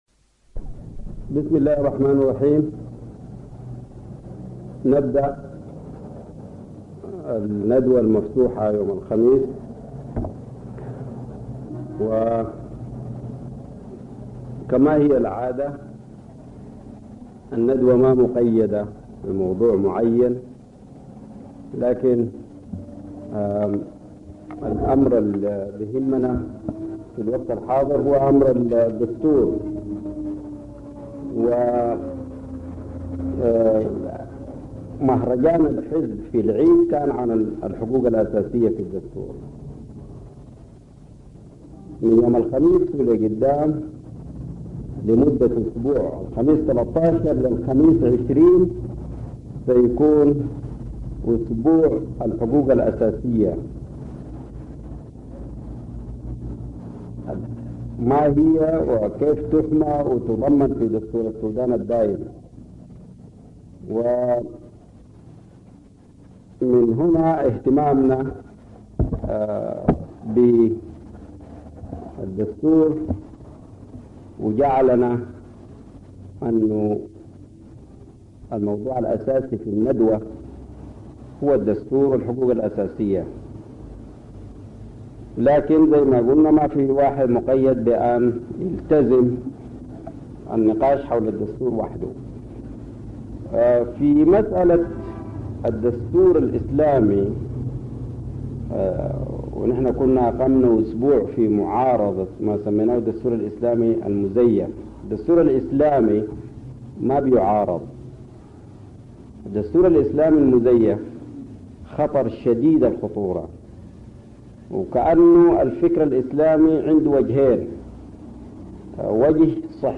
ندوة مفتوحة حول الدستور والحقوق الأساسية